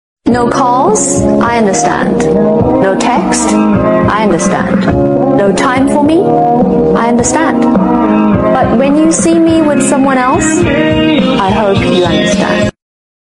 Best Motivational Speech. Life Lesson, sound effects free download